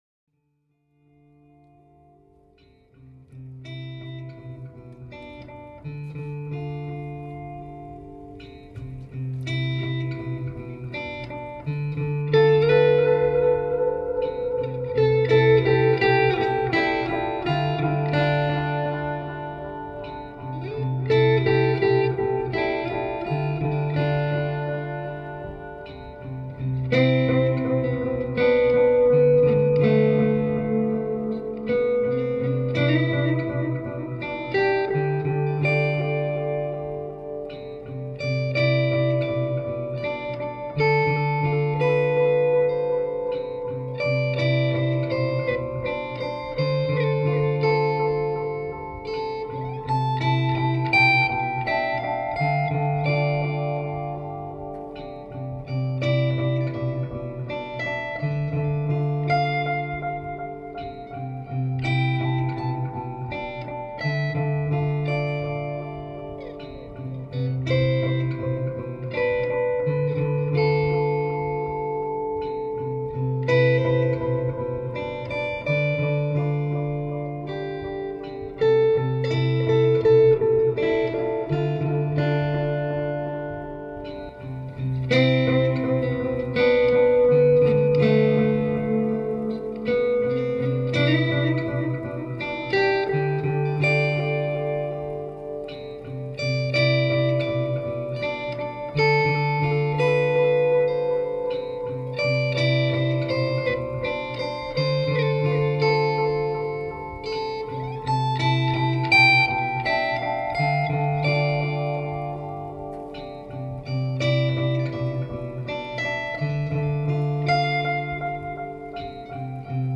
Soundtrack improvised and recorded